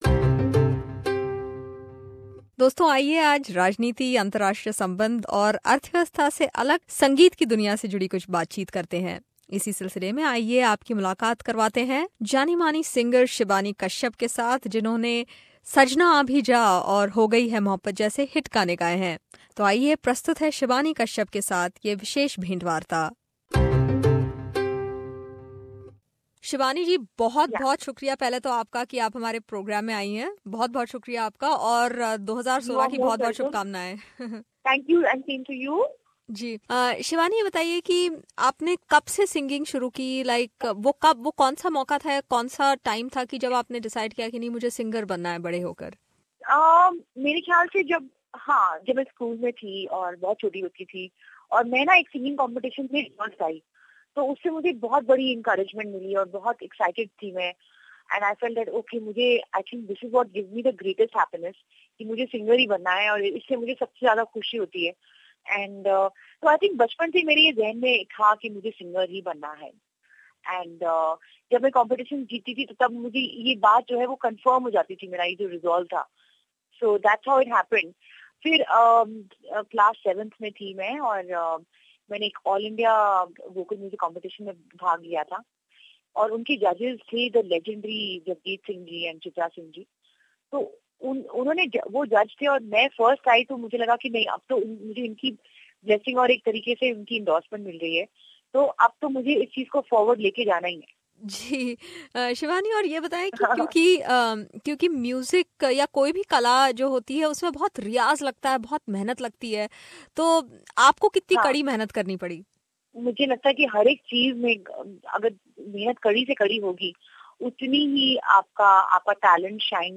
Catch this very special interview with vivacious singer Shibani Kashyap who has sung super hit numbers like 'Sajna Aa Bhi Ja' and 'Ho Gayi Hai Mohabbat Tumse'.